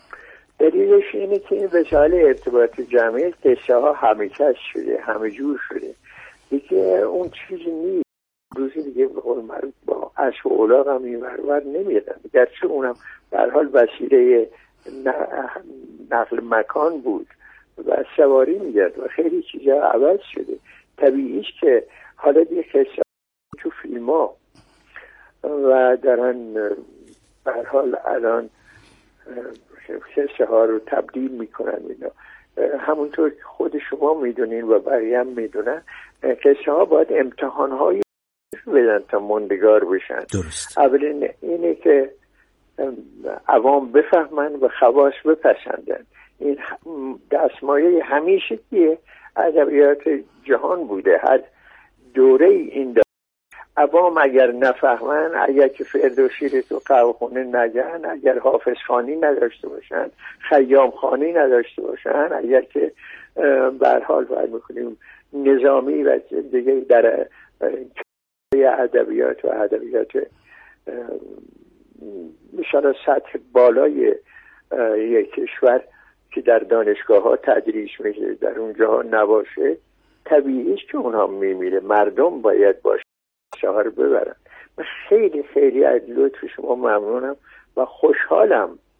همان صدای گرم و همان نوازش مهربان اصوات بیانش؛ اما اینبار آرام‌تر، بریده‌تر و ظریف‌تر!
با همان صدای آرام‌تر از همیشه که نوایی از سپری کردن دوران نقاهت عمل جراحی داشت، آرام خندید و دل به این گفت‌وگو داد.